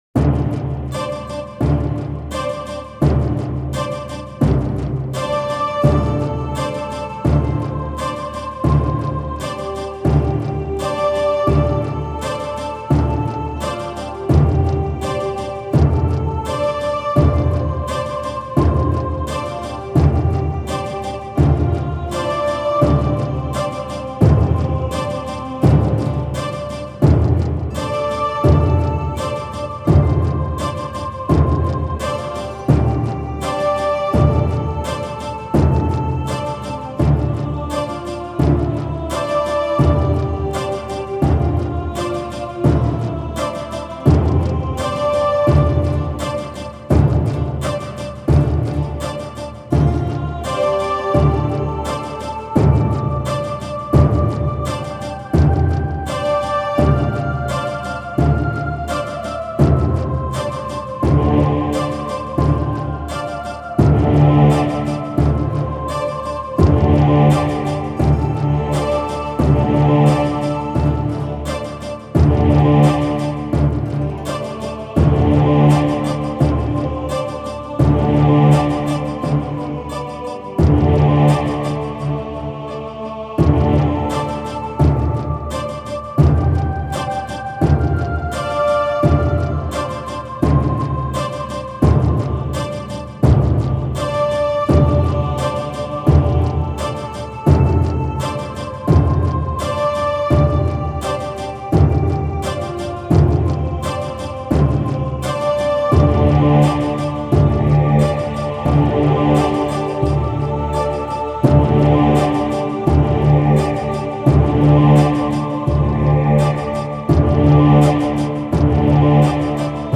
Epic Soundtrack.